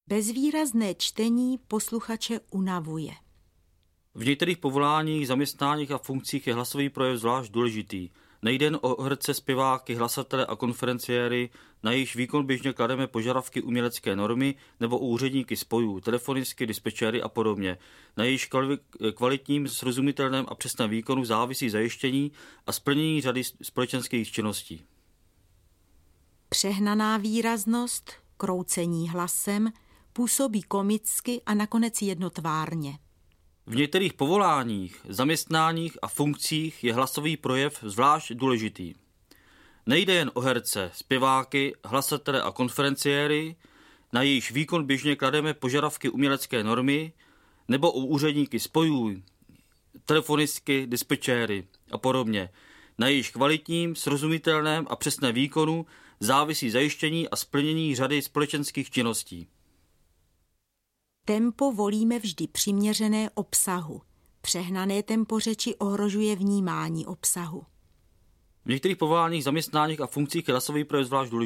Kultura mluveného slova: Živé ukázky mluveného projevu
1. Kultura mluveného slova. Příklady z řečnické praxe